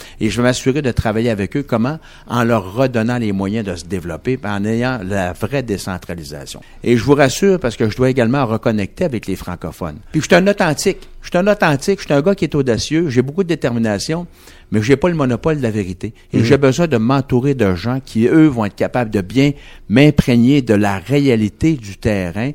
En entrevue à Radio Beauce, il estime être le mieux placé pour redonner l’étiquette économique au parti.